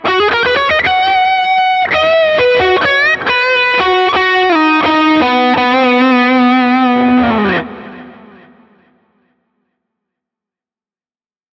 Dieser schöne Leadsound...
Der klassische Landshuter Leadsound.